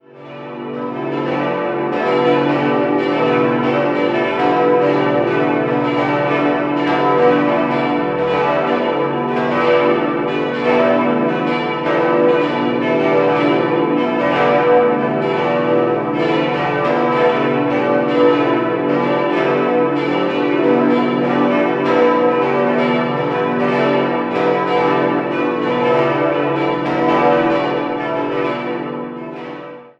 Der 5-stimmiges Geläut: h°-d'-e'-fis'-a'
Augsburg-Lechhausen Pankratius.mp3